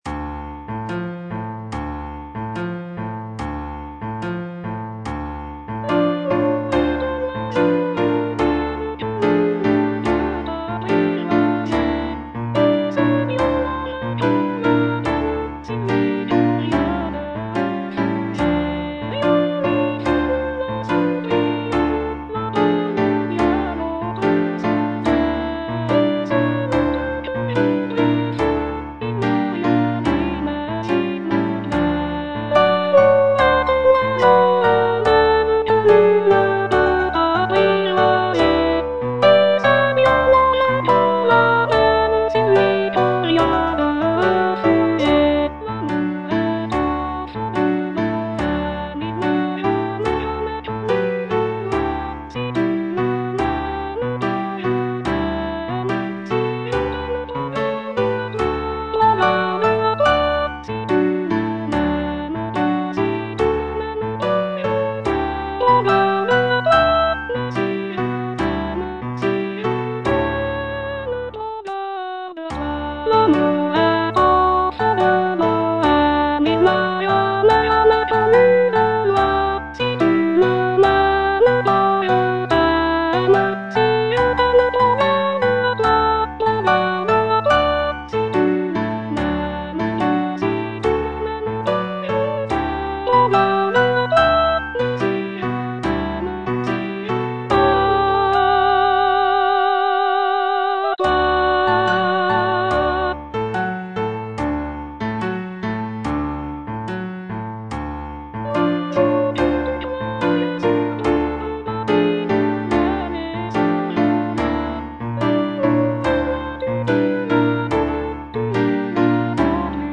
G. BIZET - CHOIRS FROM "CARMEN" Habanera (soprano II) (Voice with metronome) Ads stop: auto-stop